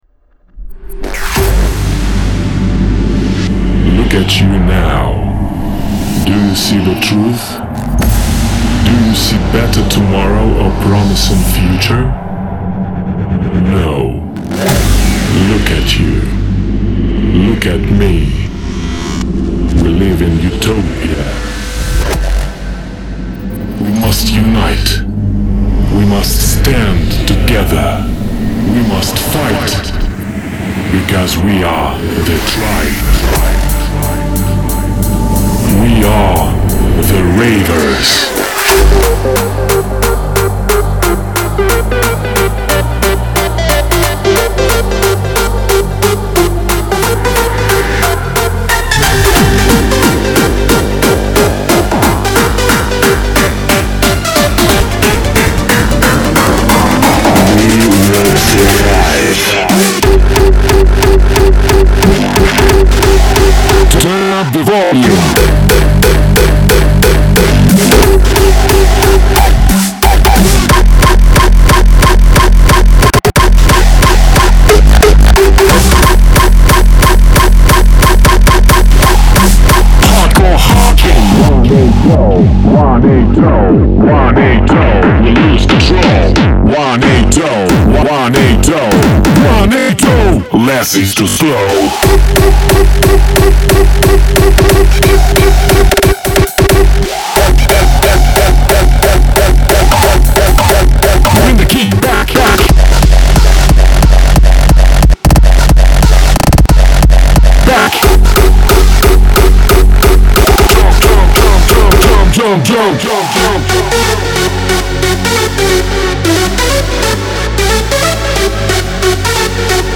打破墙壁的强大踢腿，像辣椒人声一样炽热！扭曲，充满活力和独特！
•180 BPM
•30 Vocal Shouts
•72 Hardcore Kicks + 72 Unmastered Versions
•74 Bonus Loops From Demo (Synth, Melody, Drum & FX Loops)